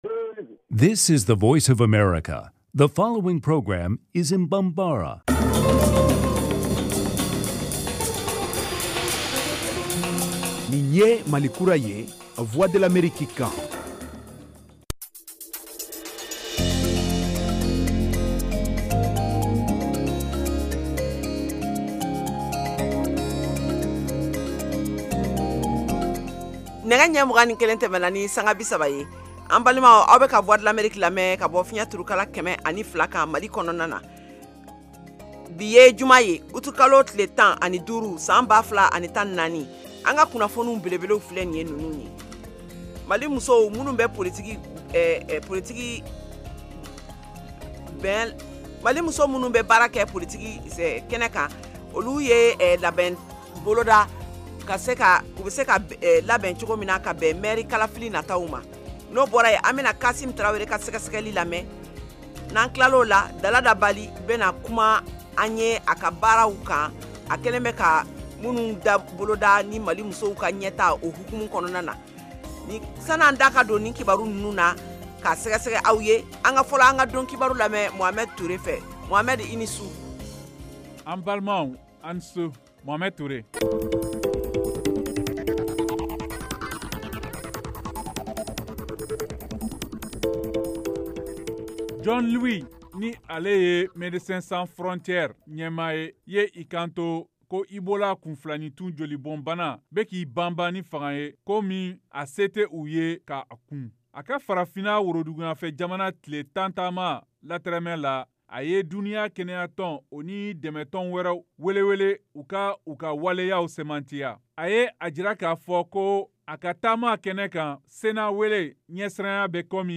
Emission quotidienne en langue bambara
en direct de Washington